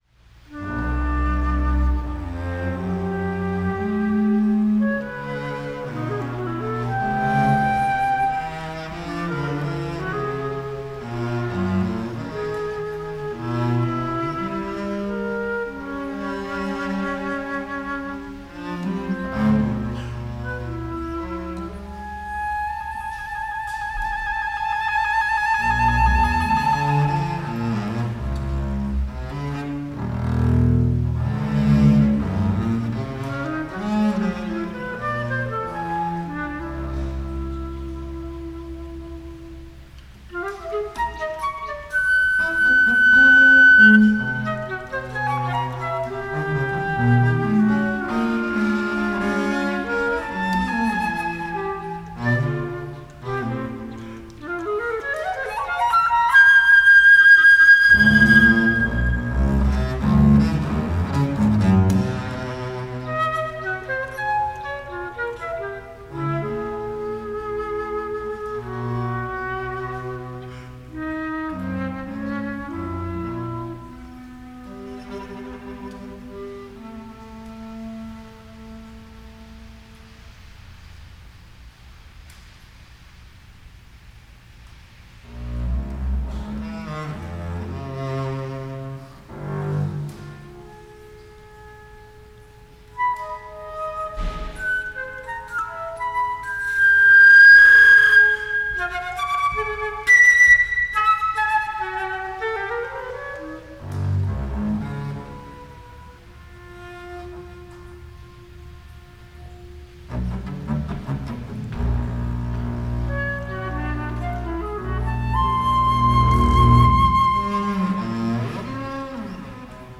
Flute and double bass